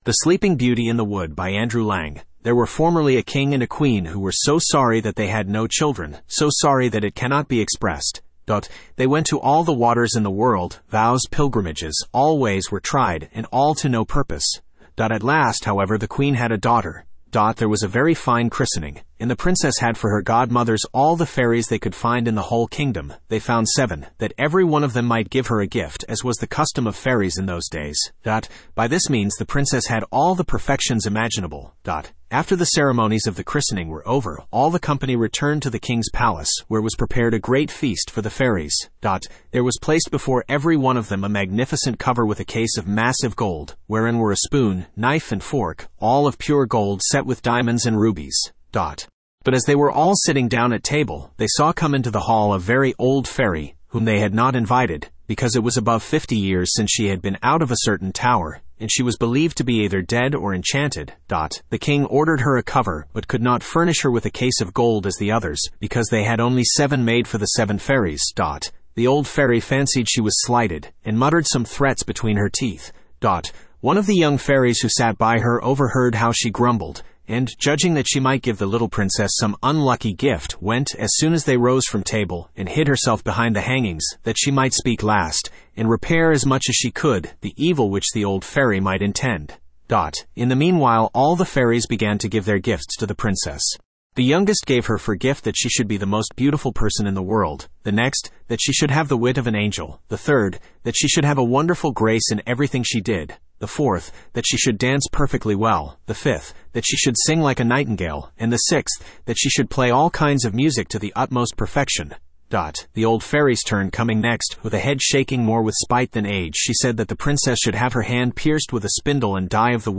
Spoken Ink « The Blue Fairy Book The Sleeping Beauty In The Wood Studio (Male) Download MP3 There were formerly a king and a queen, who were so sorry that they had no children; so sorry that it cannot be expressed.
the-sleeping-beauty-in-the-wood-en-US-Studio-M-bc4a1533.mp3